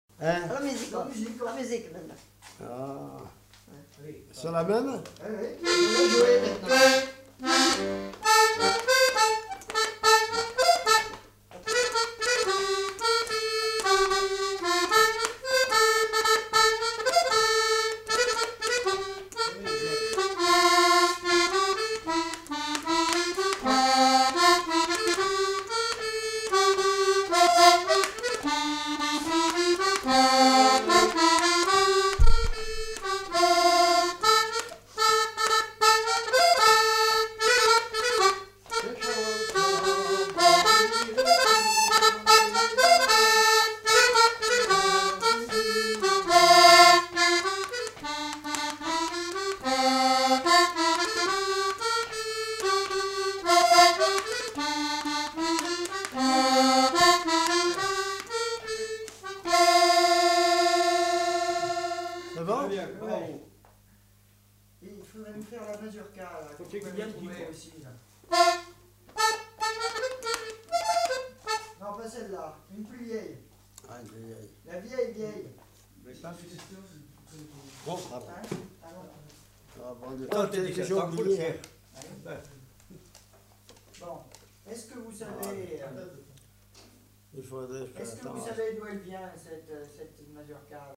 Aire culturelle : Auvergne
Lieu : Peschadoires
Genre : morceau instrumental
Instrument de musique : accordéon
Danse : valse